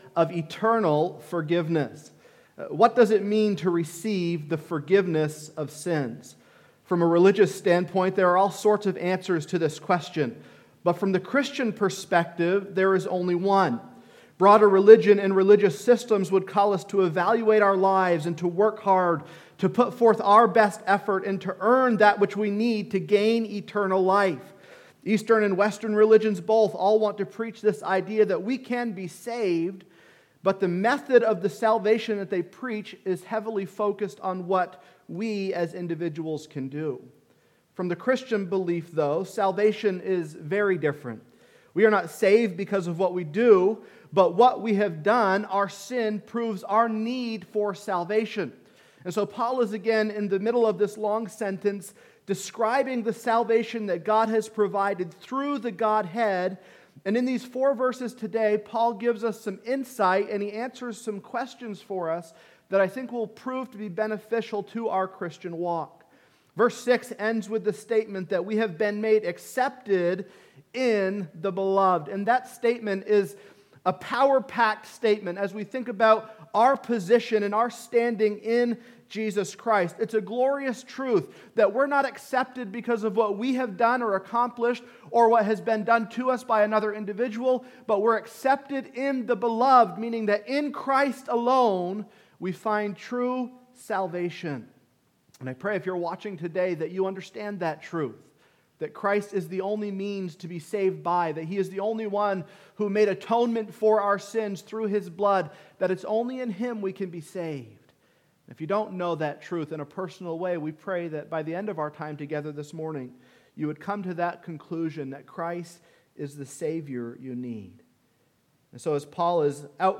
Sermons by Northside Baptist Church